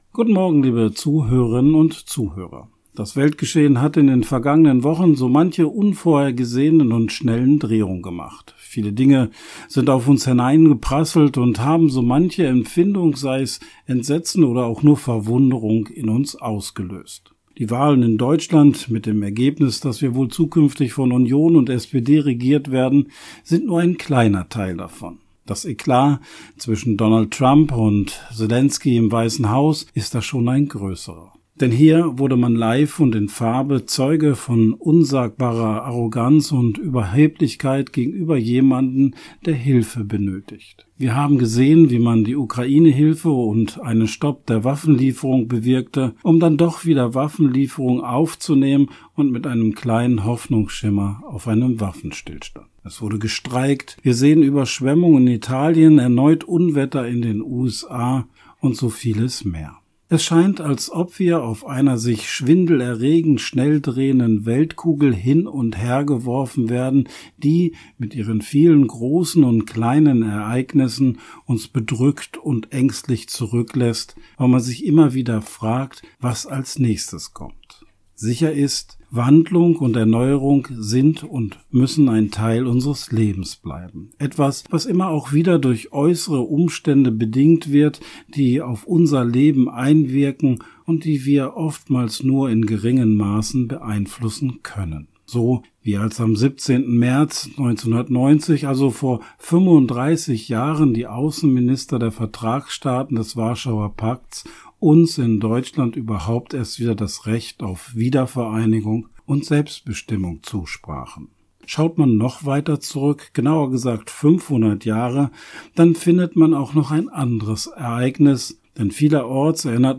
Radioandacht vom 17. März